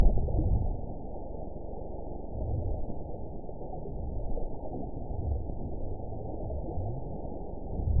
event 918186 date 07/29/23 time 04:17:35 GMT (2 years, 3 months ago) score 5.94 location TSS-AB04 detected by nrw target species NRW annotations +NRW Spectrogram: Frequency (kHz) vs. Time (s) audio not available .wav